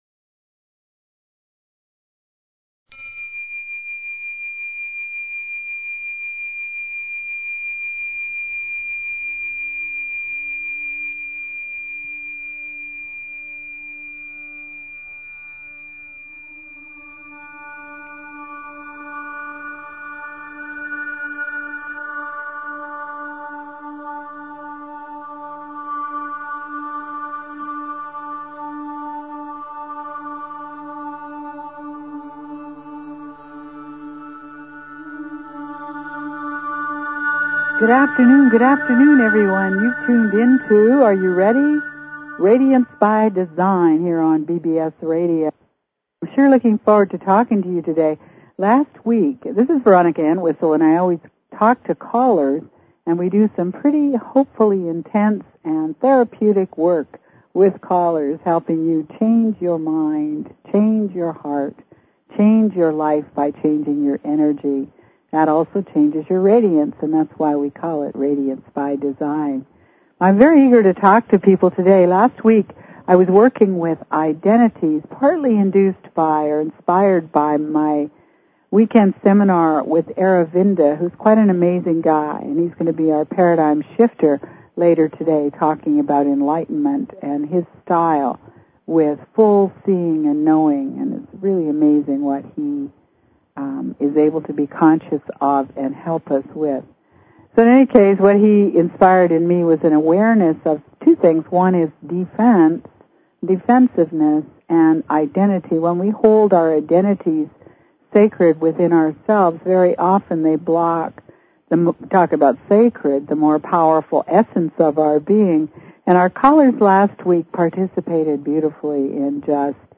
Radiance by Design continues with YOUR IDENTITY, finding out what it is, and what blocks and opens the heart. Let's explore hidden barriers to your heart, infinite love and your capacity to stretch into love. Listeners call in with their lives and their questions.